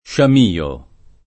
sciamio [ + šam & o ] s. m.